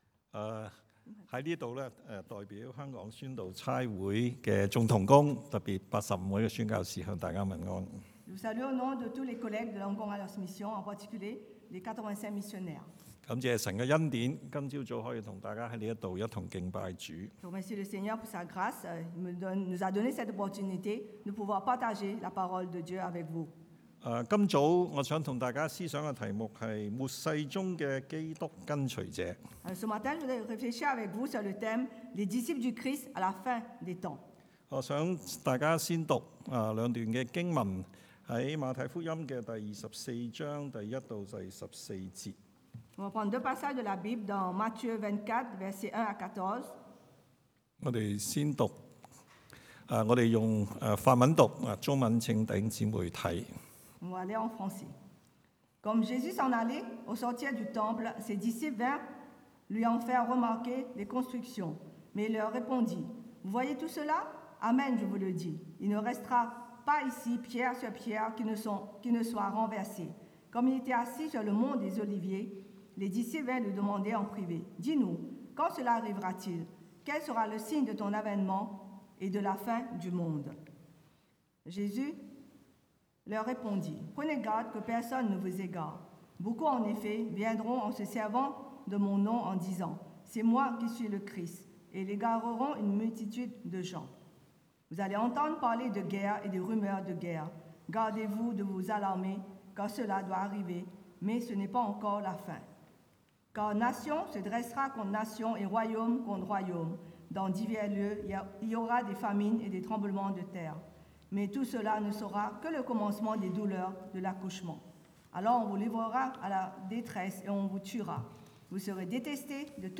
Type De Service: Predication du dimanche